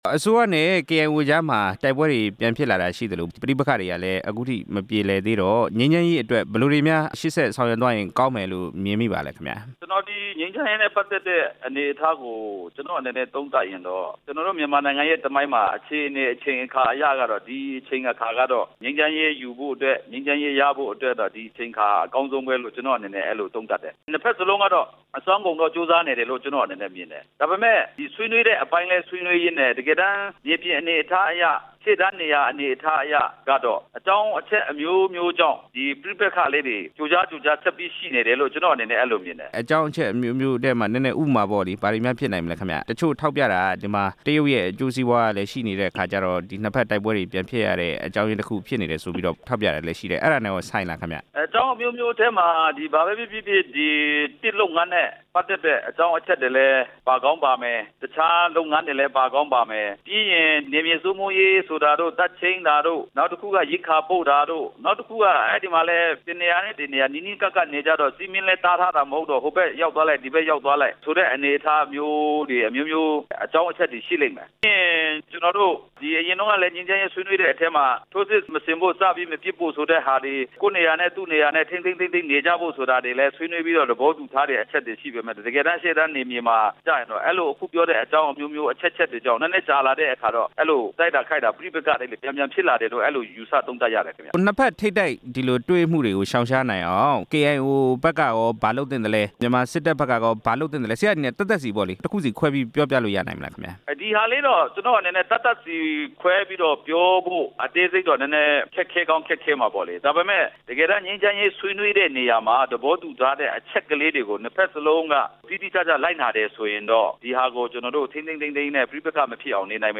ကချင်ပဋိပက္ခဖြေရှင်းရေး ကချင်လွှတ်တော်အမတ်နဲ့ မေးမြန်းချက်